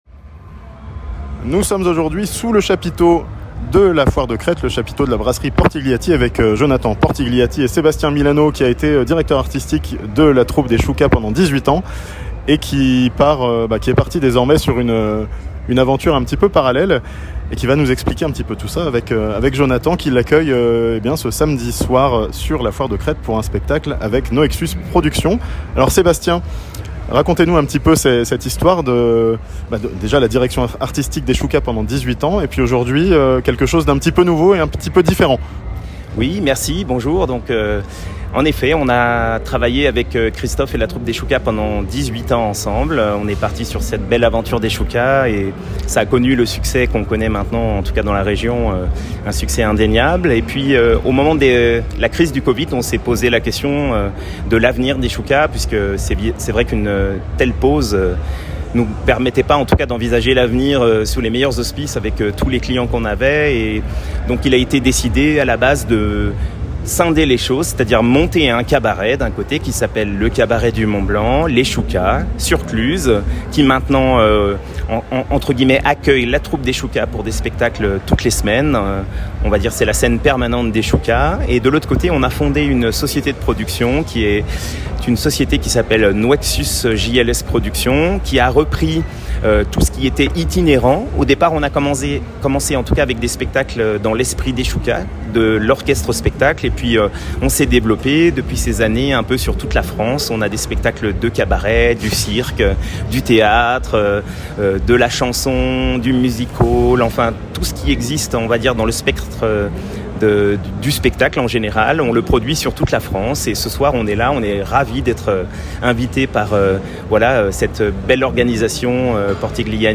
Un cabaret présenté par Noexus Productions pour clôre la Foire de Crête, à Thonon (interview)